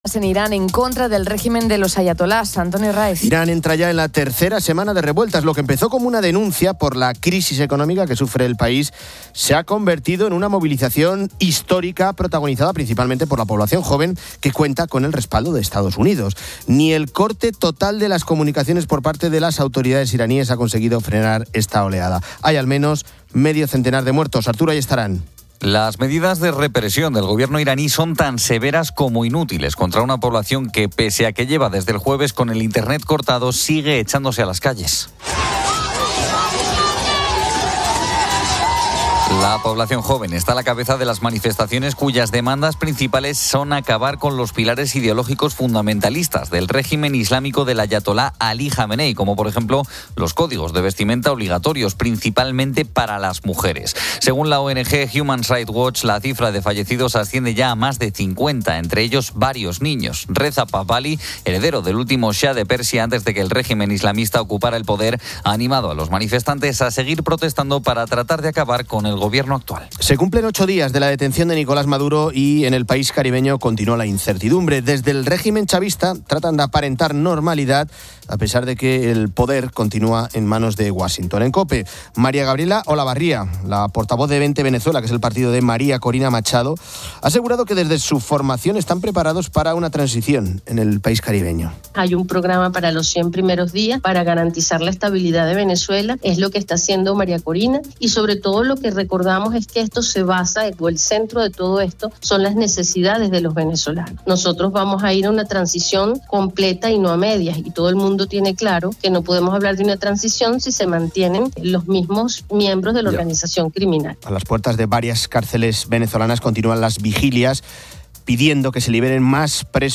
Hora completa del programa Fin de Semana de 12:00 a 14:00
Fin de Semana 12:00H | 11 ENE 2026 | Fin de Semana Rafael Yuste, neurobiólogo y director del Centro de Neurotecnología de la Universidad de Columbia nos habla de los neuroderechos, la protección de la mente humana en la era digital. Ángel Expósito continúa su viaje a Groenlandia. En la tertulia hablamos con los oyentes de esa ganga que ha encontrada en tiempo de rebajas.